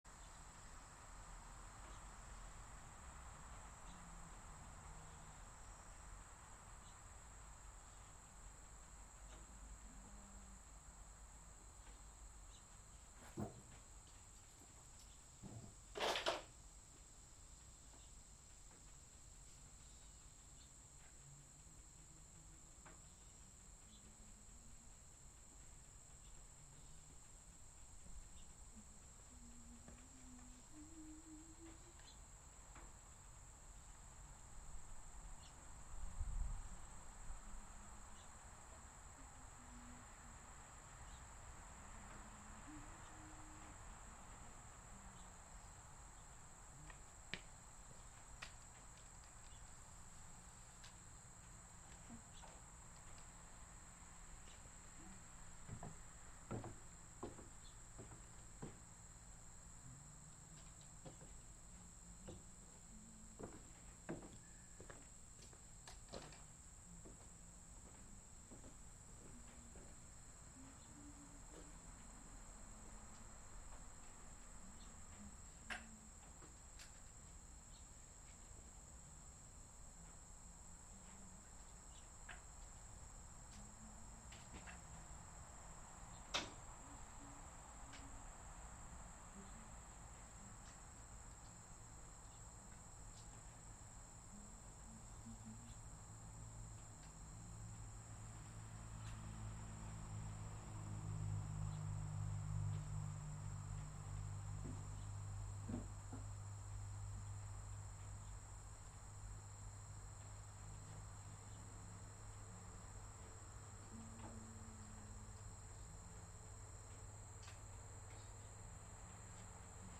Live from Art Omi: Art Omi Live (Audio)